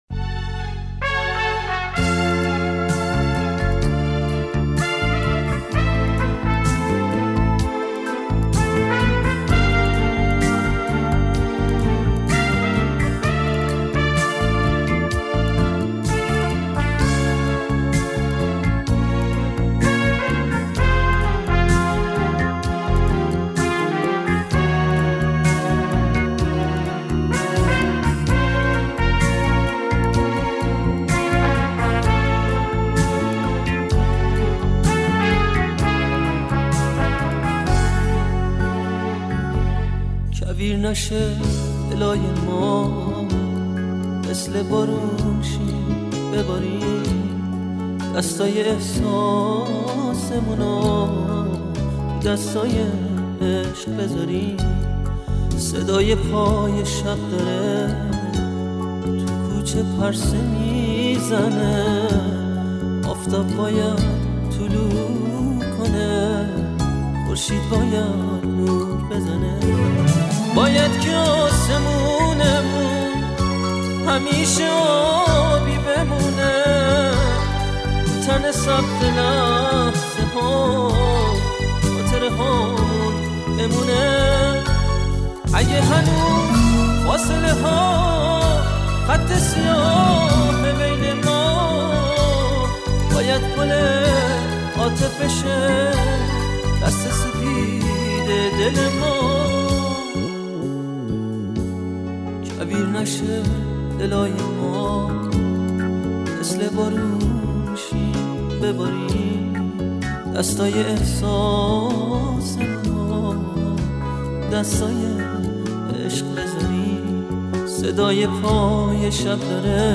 در سبک پاپ
ویلن (سلو)/گیتار
پیانو/کیبورد
گیتار الکتریک
ضبط: استودیو پاپ